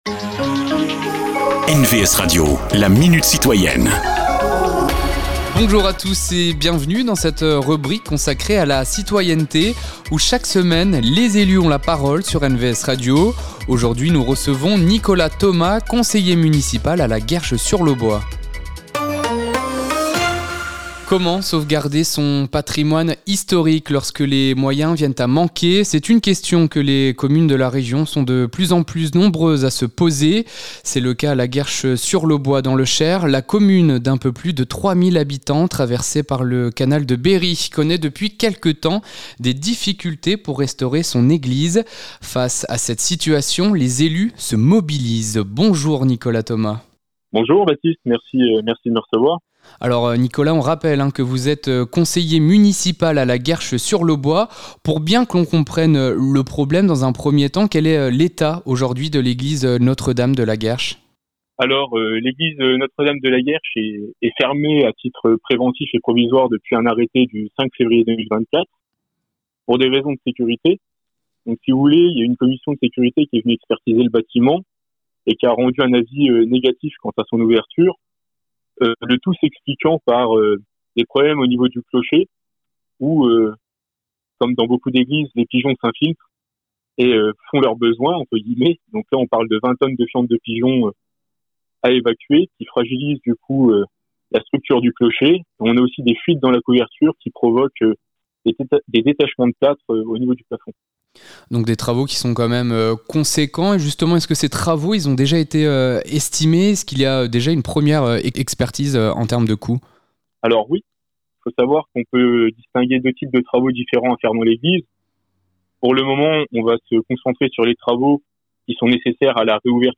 Rencontre avec ceux qui font l’actualité du territoire.
Cette semaine, Nicolas Thomas, conseiller municipal à la Guerche-sur-l'Aubois.